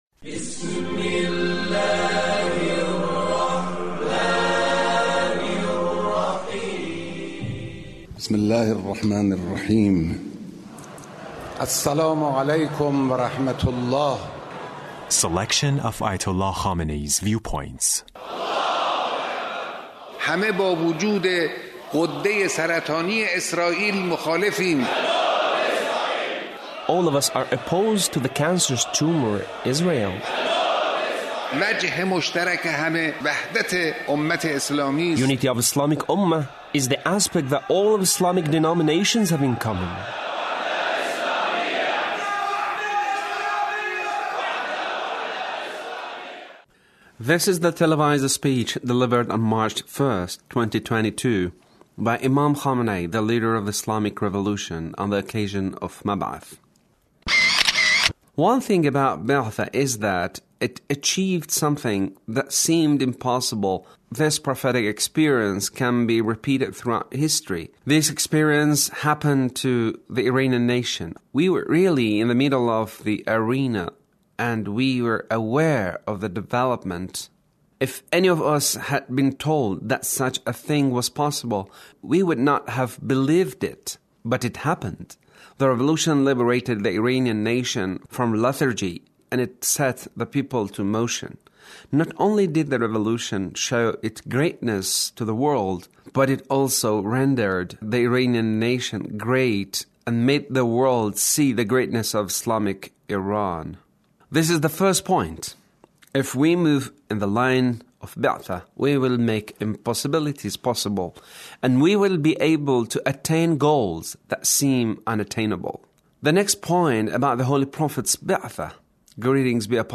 The Leader's speech about Be'that